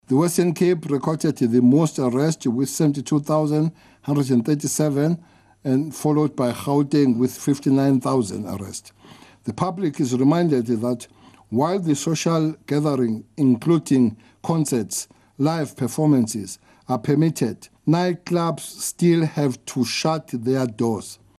He briefed the media yesterday afternoon on law enforcement during lockdown level 1.